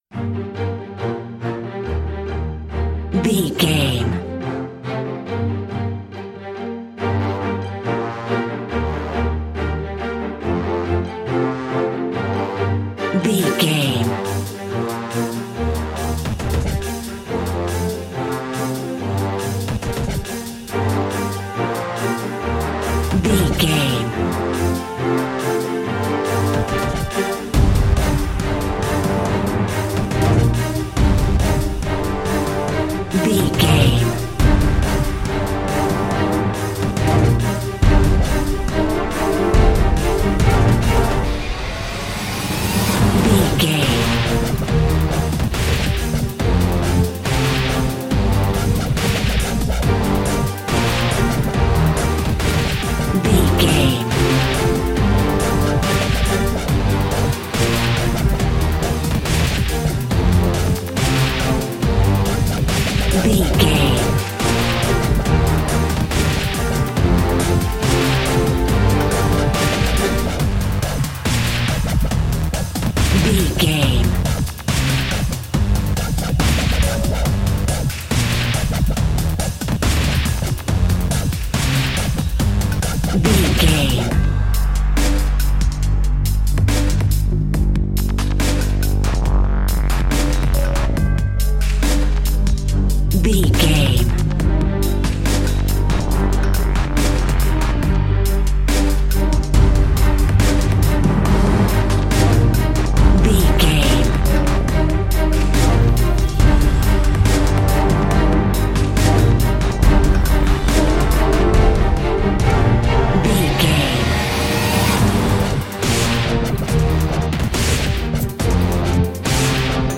Epic / Action
Aeolian/Minor
strings
drum machine
synthesiser
brass